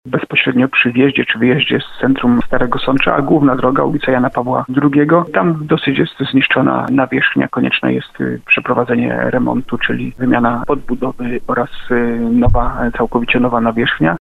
Jana Pawła II do ronda Ady Sari – tłumaczy burmistrz Jacek Lelek.